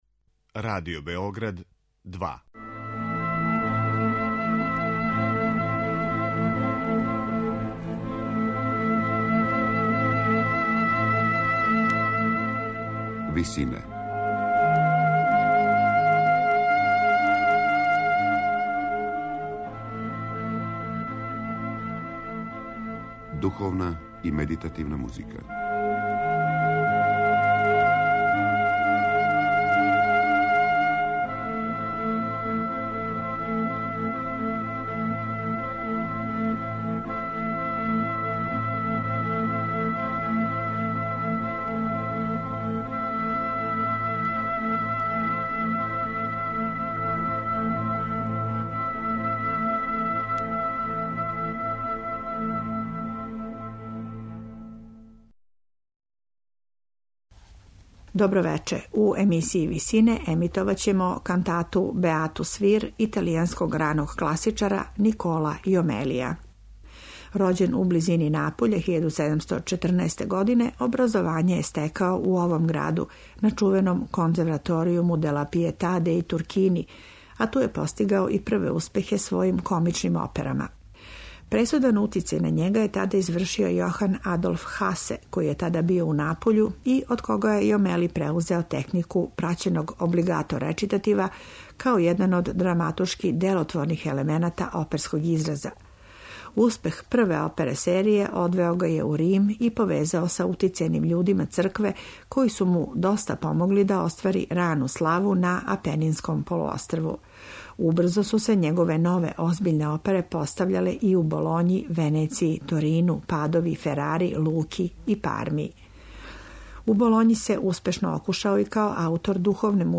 Емисија духовне и медитативне музике